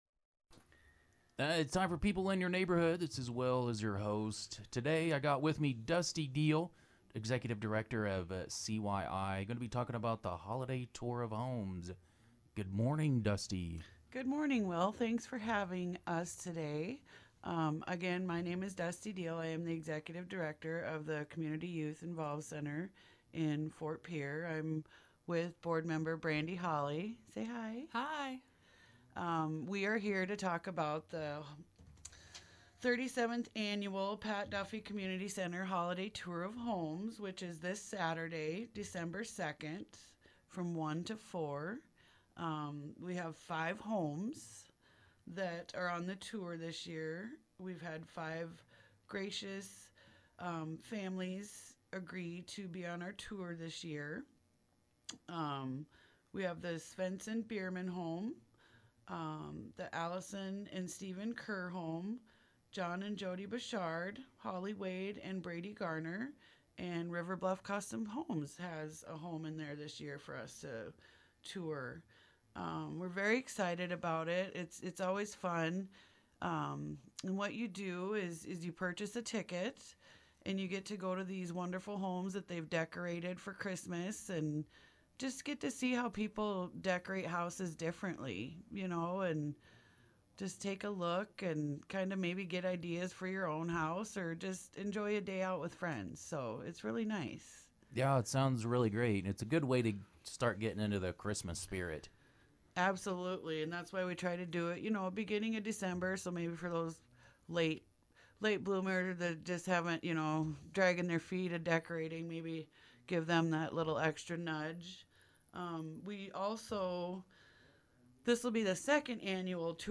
were in the KGFX studio to share the details.